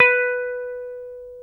Index of /90_sSampleCDs/Roland LCDP02 Guitar and Bass/GTR_Dan Electro/GTR_Dan-O 6 Str